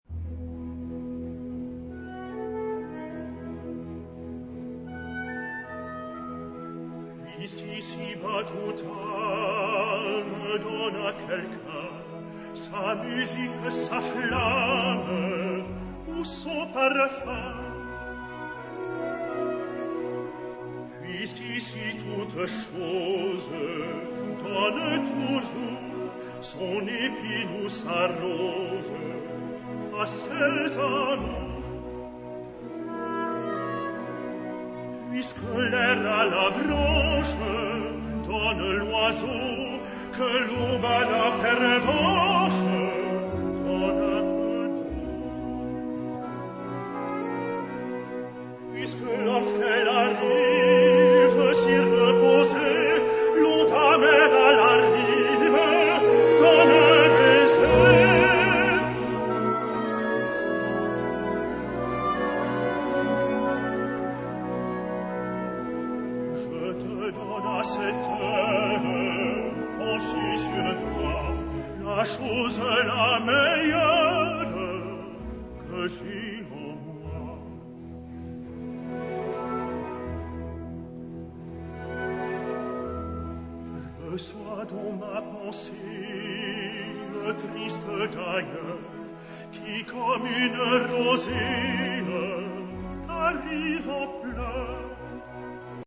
聖桑歌曲集 / 管弦樂伴奏
這是由一位男中音與男高音，分別演唱聖桑的藝術歌曲。
這些聖桑的曲子，改以管弦樂伴奏時，那種色彩繽紛的立體，